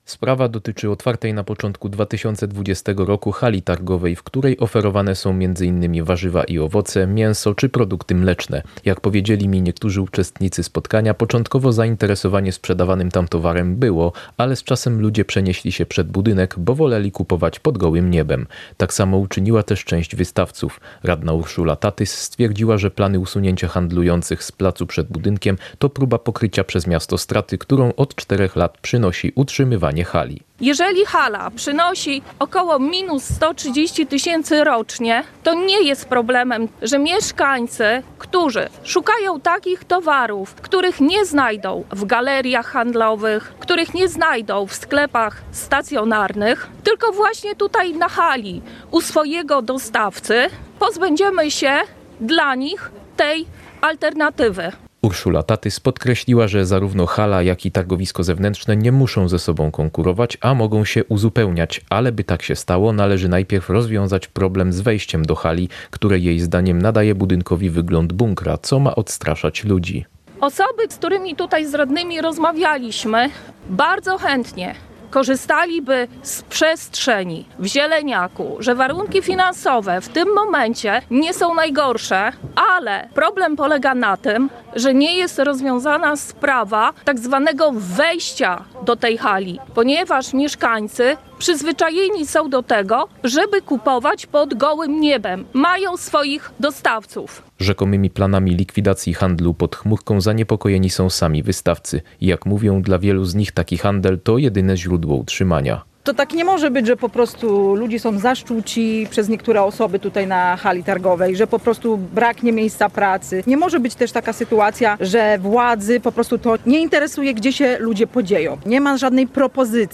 Czy targowisko w Stalowej Woli zostanie zlikwidowane? • Relacje reporterskie • Polskie Radio Rzeszów
Dziś (17.04) na targowisku odbyło się spotkanie lokalnych samorządowców z handlującymi, aby wyjaśnić tę kwestię.
Stalowa-Wola-handlarze-konta-miasto.mp3